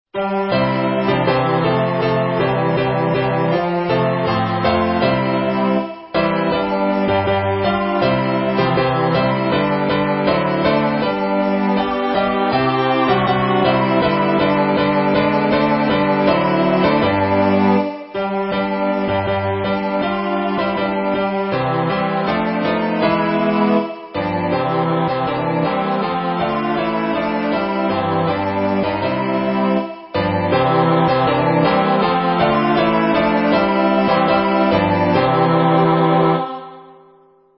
vous entendrez un fichier MP3 de son Closing Hymn, séquencé